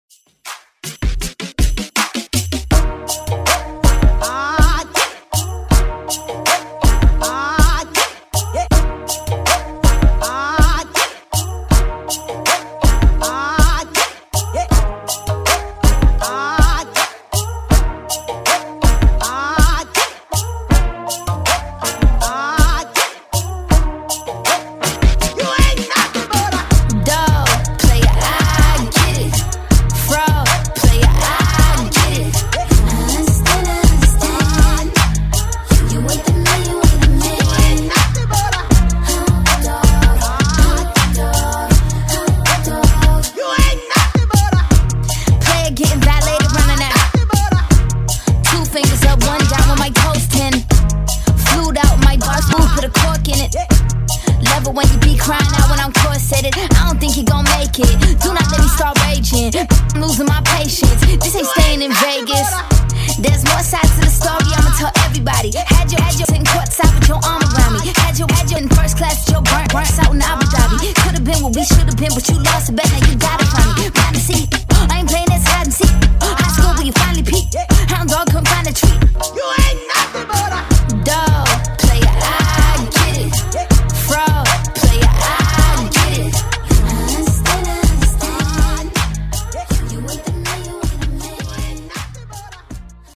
Genres: HIPHOP , R & B , RE-DRUM
Clean & Dirty BPM: 80 Time